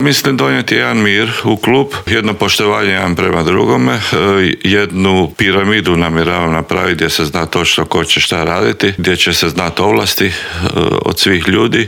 Naš gost bio je i novi predsjednik Dinama Velimir Zajec: